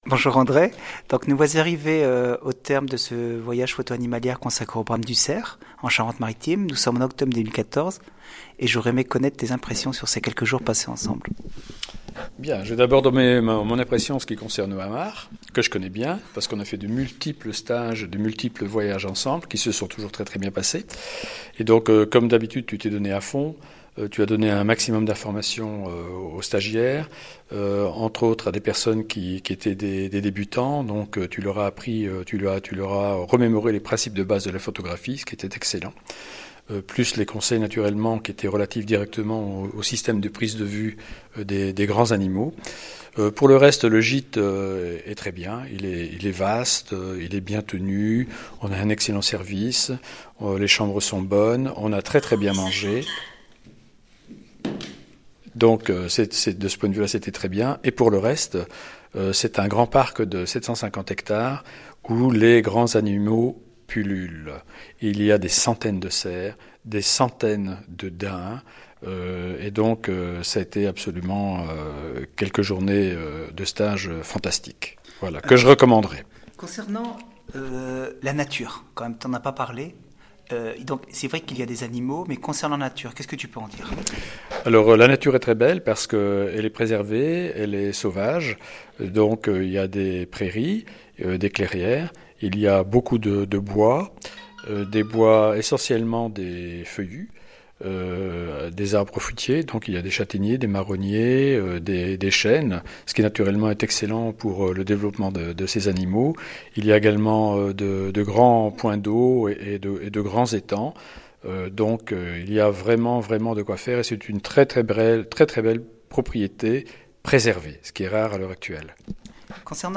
Témoignages écrits et sonores des participants